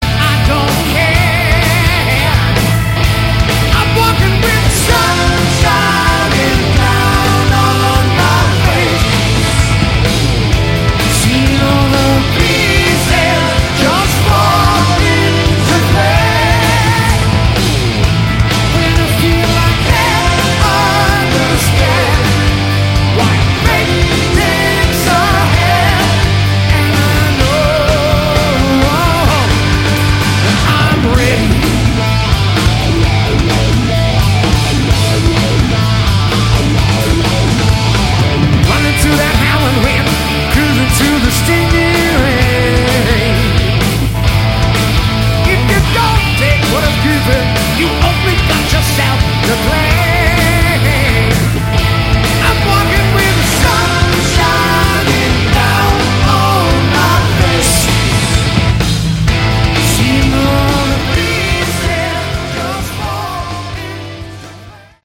Category: Classic Hard Rock